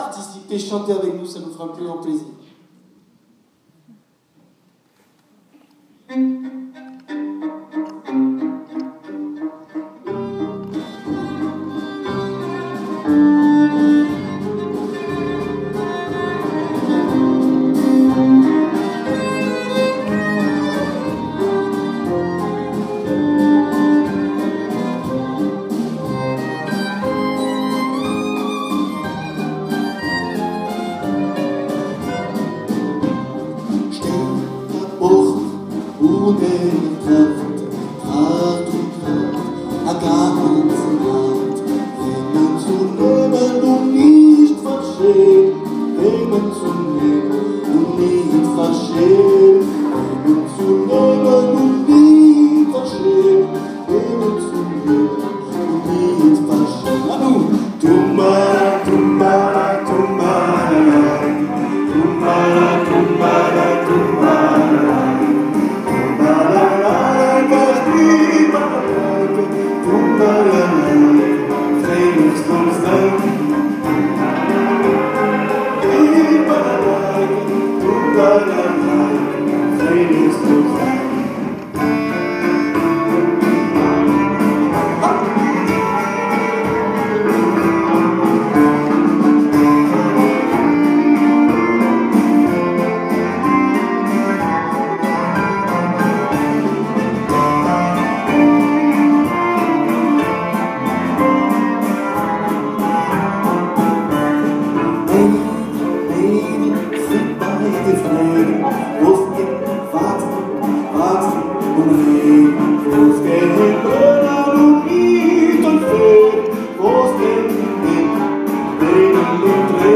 un enregistrement pour chanter ensemble
cymbalum
accordéon
clarinette